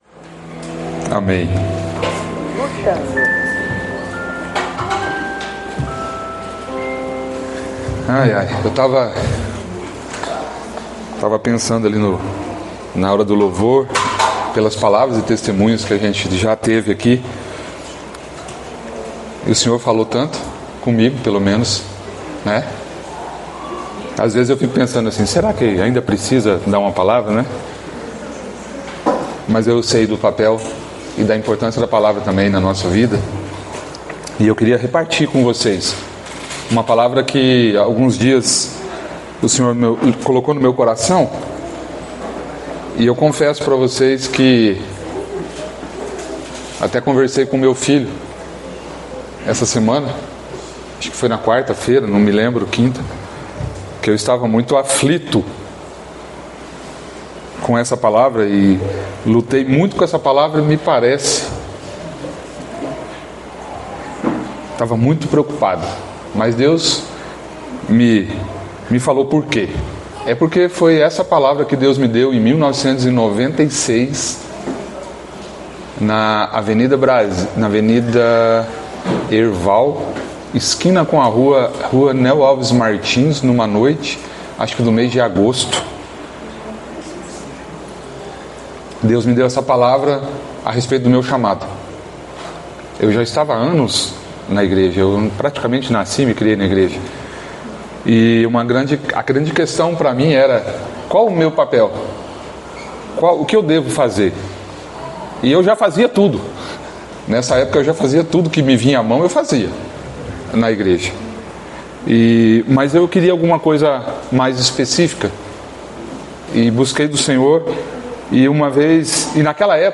Palavras ministradas